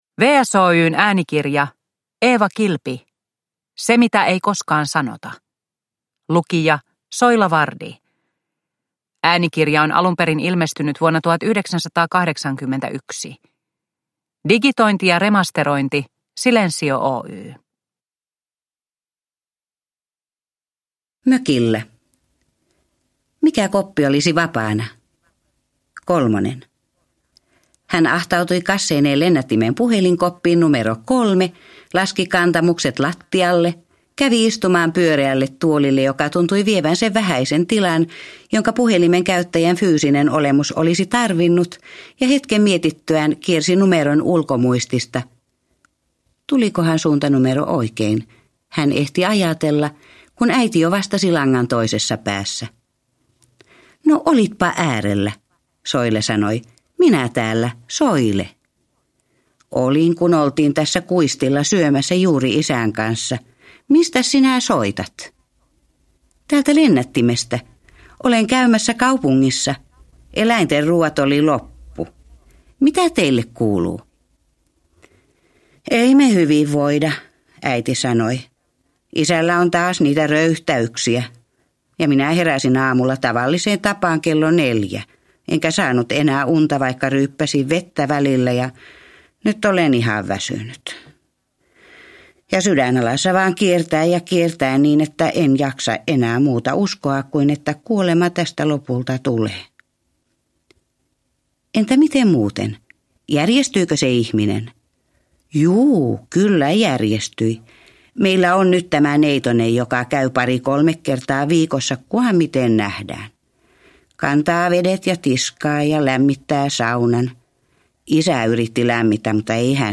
Se mitä ei koskaan sanota – Ljudbok – Laddas ner